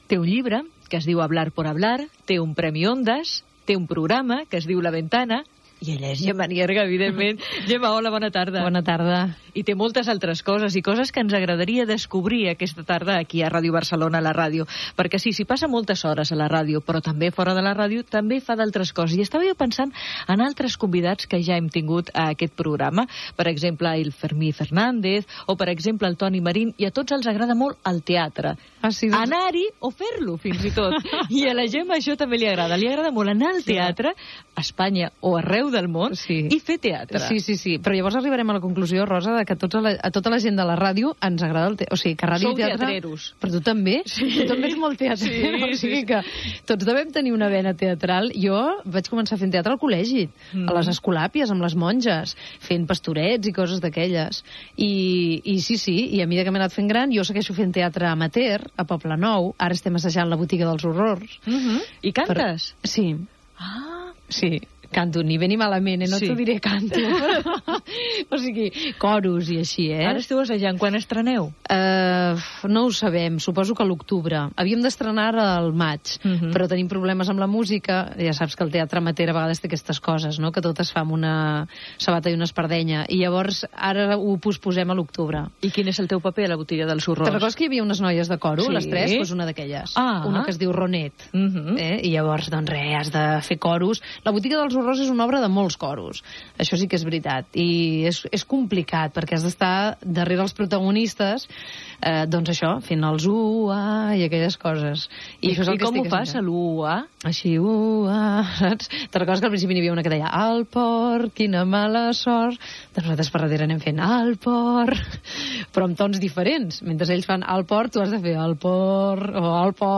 Entrevista a Gemma Nierga, presentadora de "La Ventana".